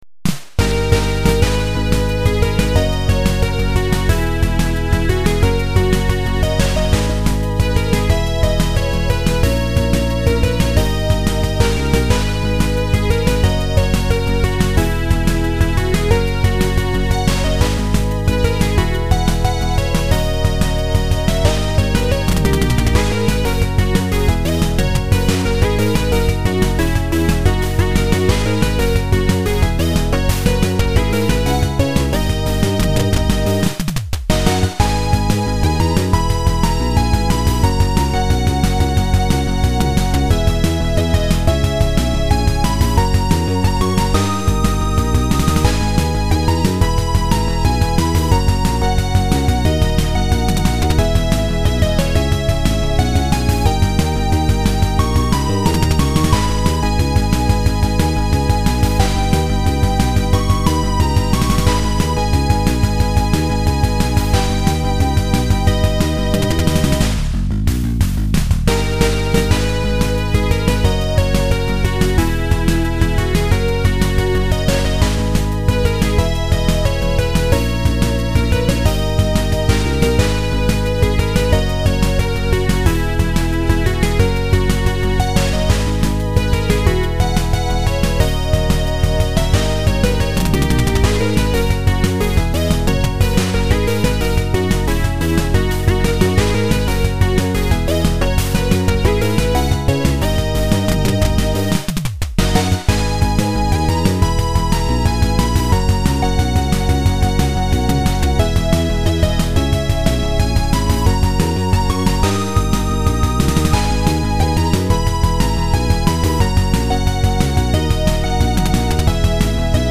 PSG